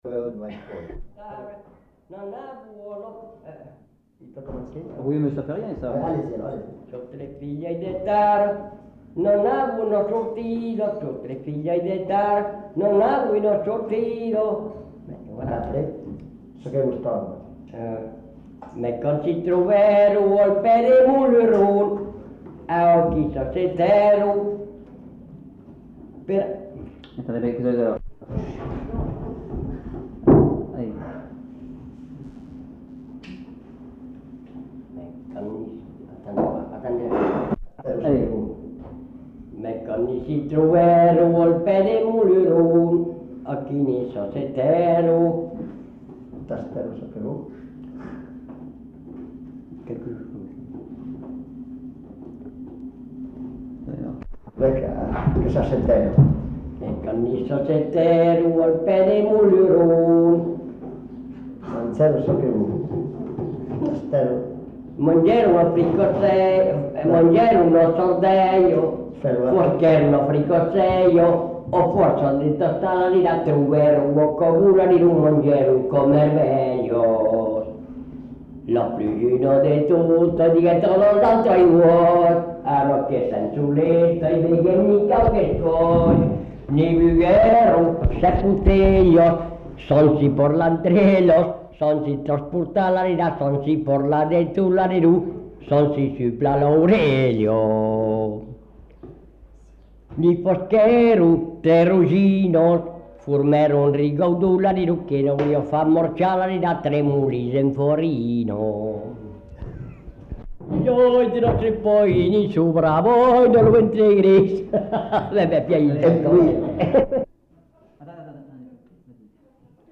Aire culturelle : Rouergue
Lieu : Saint-Laurent-d'Olt
Genre : chant
Effectif : 1
Type de voix : voix d'homme
Production du son : chanté
Notes consultables : L'interprète s'interrompt à plusieurs reprises. Il se présente en fin de séquence.